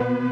Freq-lead20.ogg